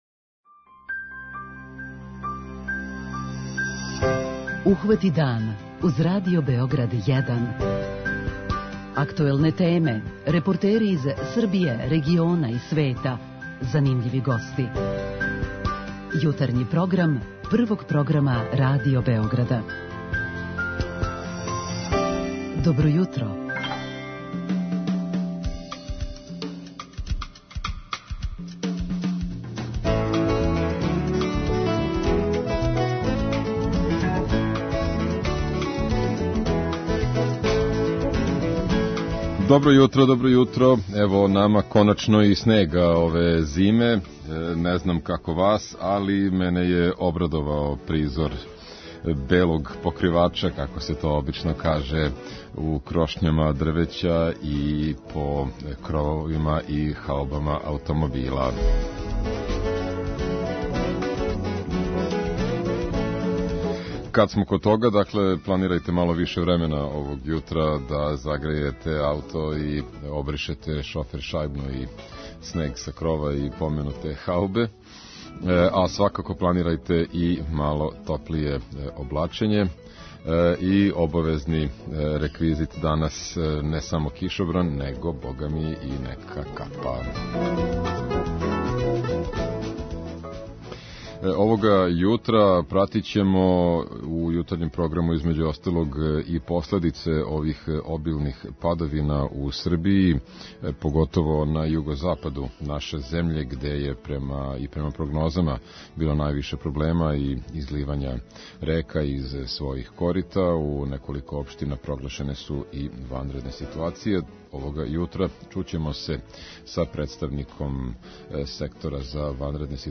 Министарство рударства и енергетике и ове године наставиће да субвенционише грађане који желе да њихова домаћинства уштеде на енергентима, а о тој теми разговараћемо с Миланом Мацуром, помоћником министра. Ова тема биће и наше 'Питање јутра' па ћемо чути од слушалаца да ли су већ изводили или планирају неке радове у стану или кући како би повећали енергетску ефикасност.